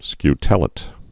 (sky-tĕlĭt, skytl-āt)